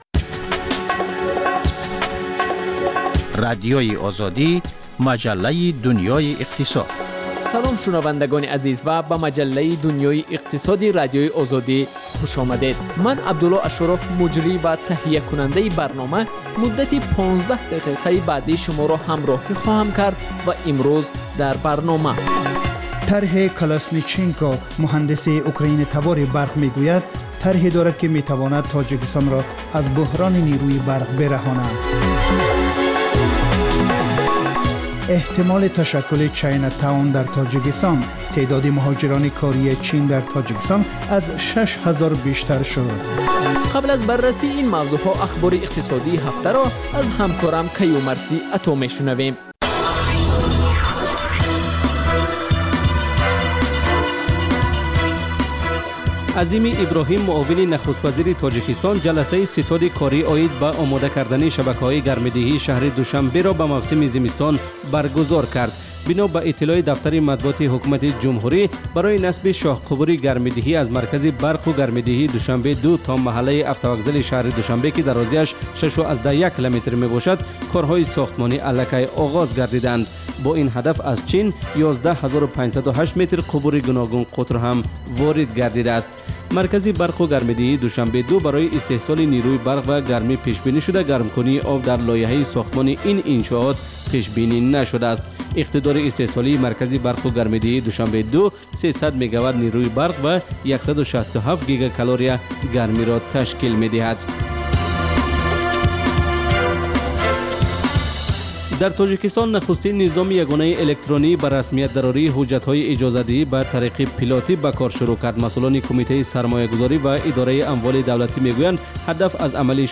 Гуфтугӯи ошкоро бо шахсони саршинос ва мӯътабари Тоҷикистон, сиёсатмадорону ҷомеашиносон, ҳунармандону фарҳангиён